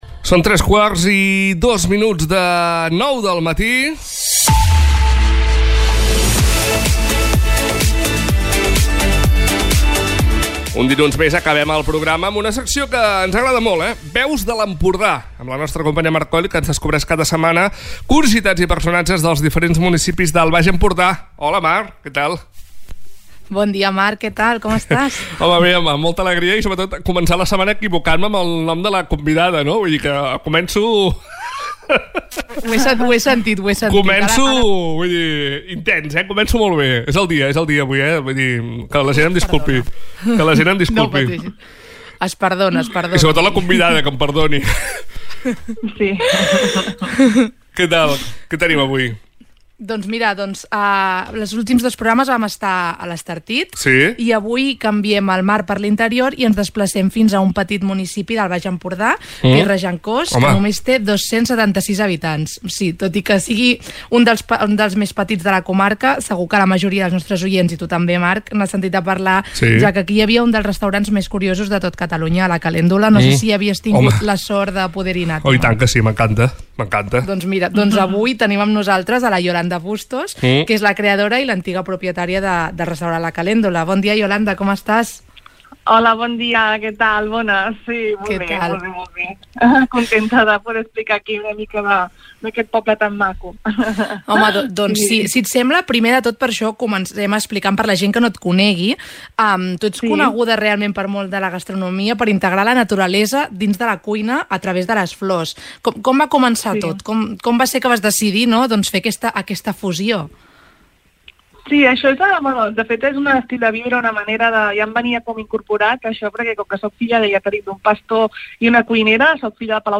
En l'entrevista